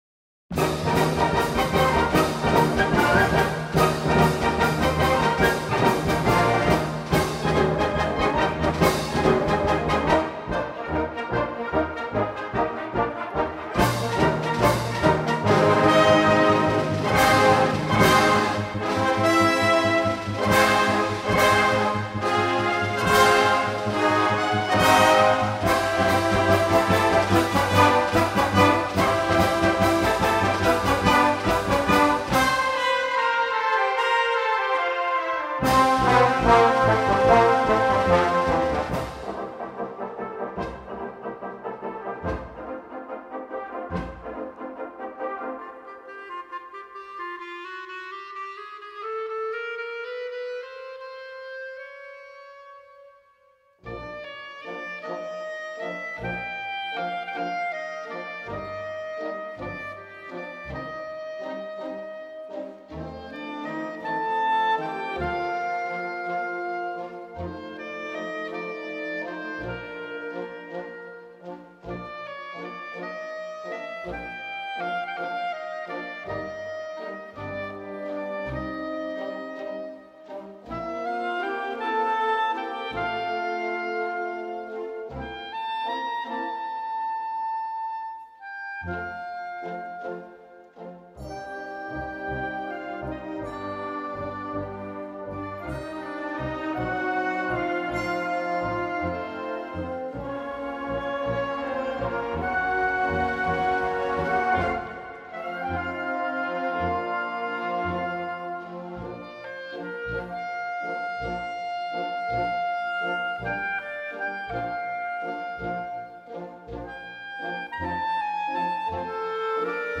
Gattung: Ouvertüre
A4 Besetzung: Blasorchester Zu hören auf